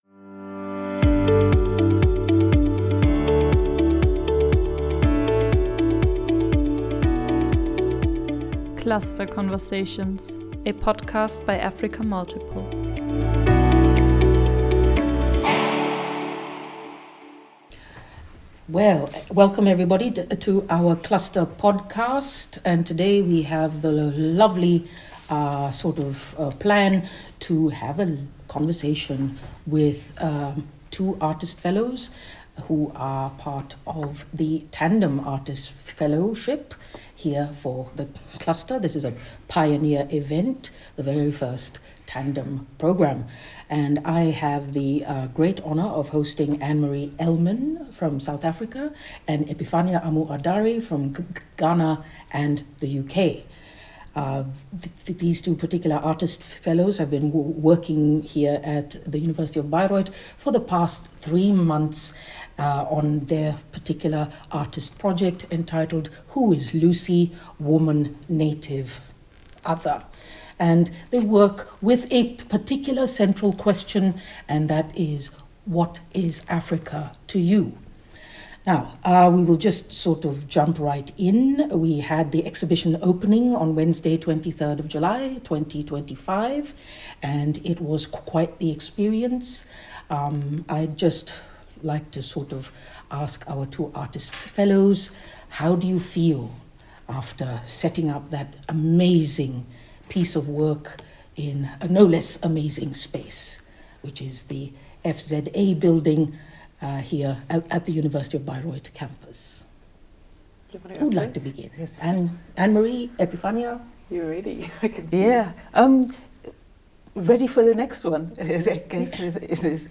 Together, they explore how intellectual curiosity, personal experience, and collaborative inquiry shape their work within the Cluster – and beyond. Tune in for an inspiring conversation that blends reflection, insight, and a touch of scholarly playfulness.